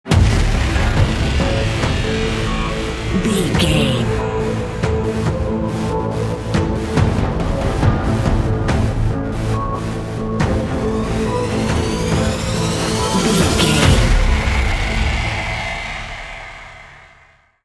Epic / Action
Aeolian/Minor
Fast
synthesiser
percussion